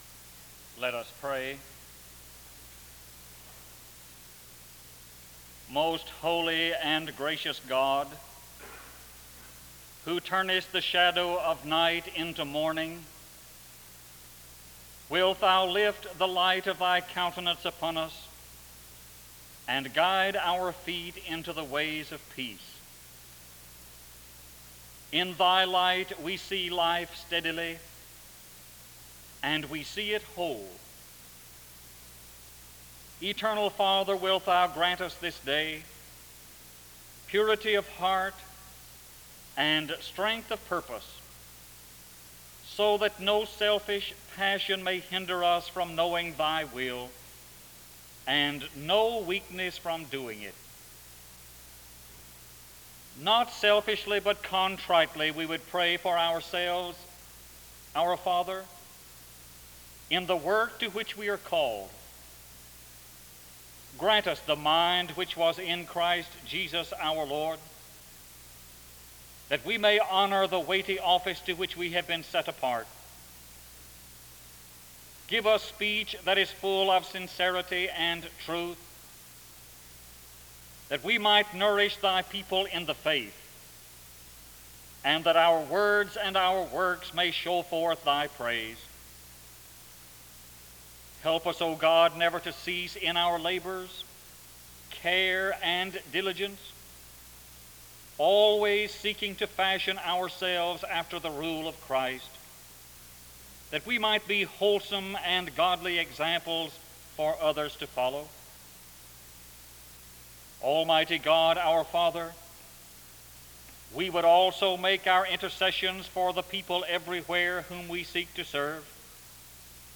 The service begins with a prayer (0:00-3:15). After which, the speaker reads a portion of Scripture from Matthew 12 (3:16-5:35). There is then a period of singing (5:36-8:17).